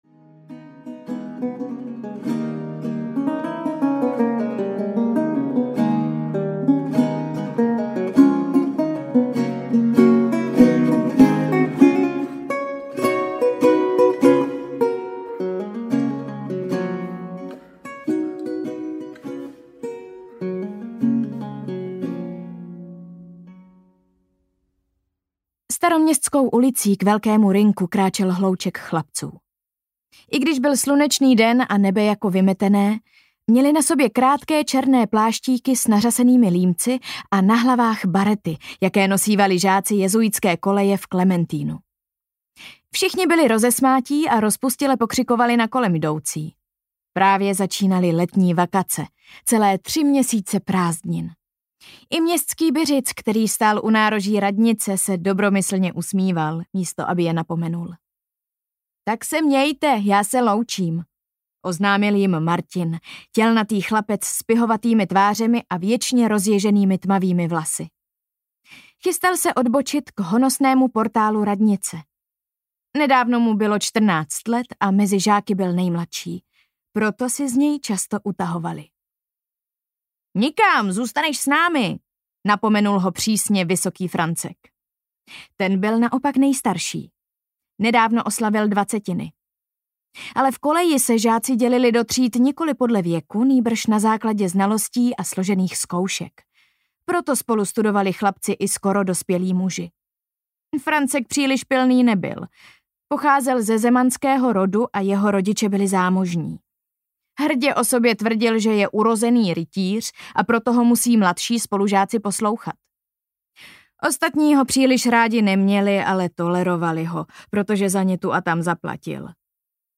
Dům ztracených duší audiokniha
Ukázka z knihy
• InterpretEva Josefíková